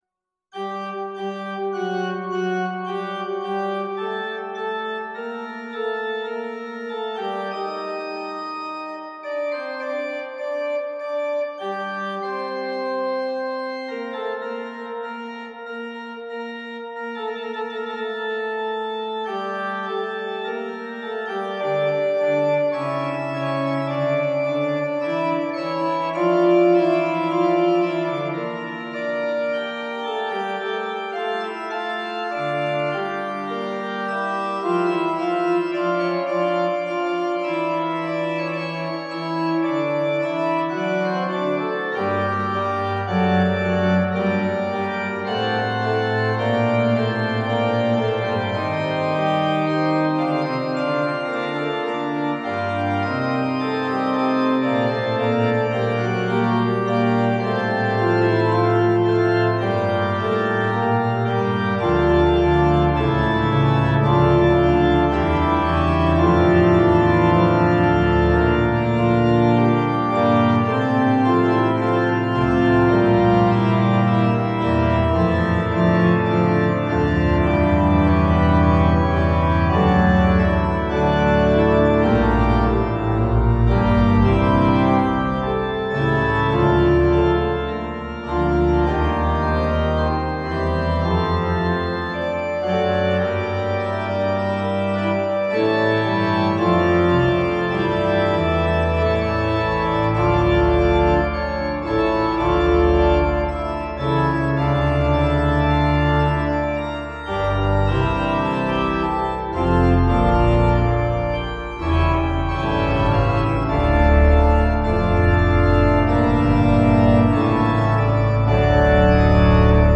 Testbericht – Organteq Kirchenorgel von Modartt
live auf seinem heimischen Orgelspieltisch eingespielt hat: